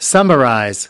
27. summarize (v) /ˈsʌməraɪz/: tóm tắt